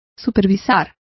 Complete with pronunciation of the translation of supervised.